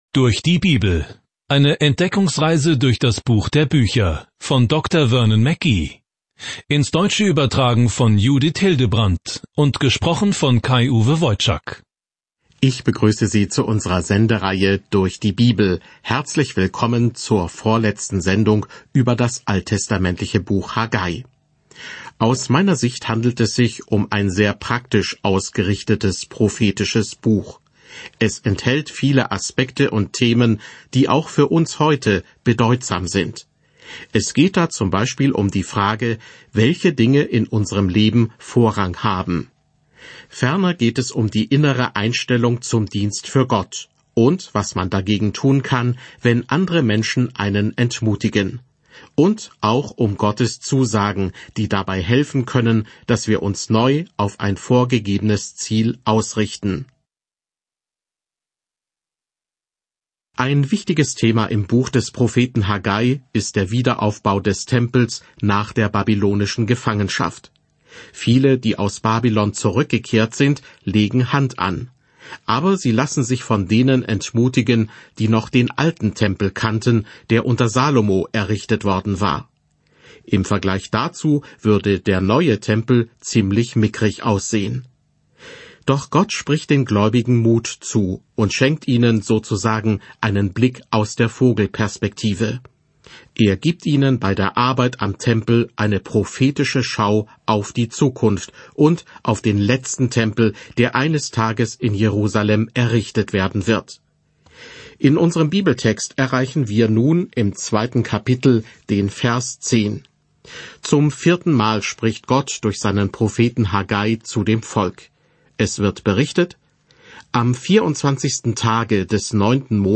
Die Heilige Schrift Haggai 2:10-14 Tag 7 Diesen Leseplan beginnen Tag 9 Über diesen Leseplan Haggais „Erledige es“-Haltung drängt ein zerstreutes Israel dazu, den Tempel wieder aufzubauen, nachdem es aus der Gefangenschaft zurückgekehrt ist. Reisen Sie täglich durch Haggai, während Sie sich die Audiostudie anhören und ausgewählte Verse aus Gottes Wort lesen.